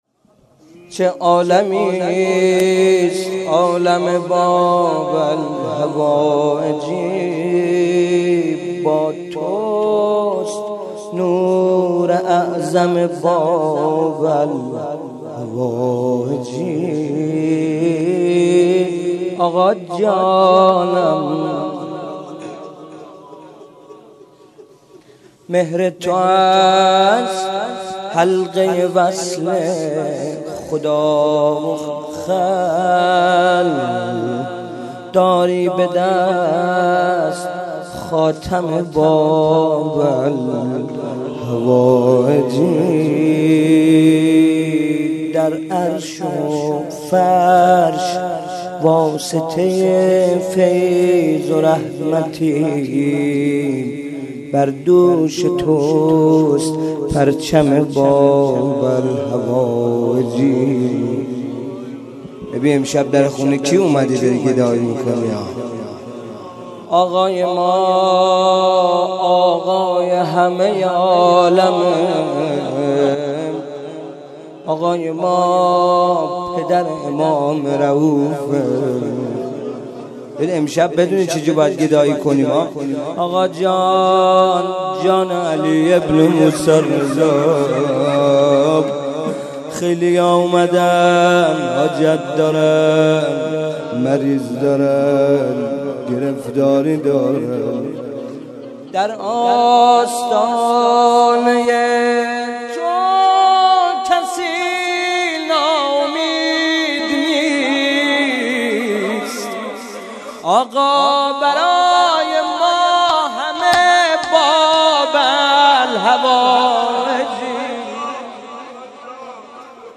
مداح : محمدرضا طاهری قالب : روضه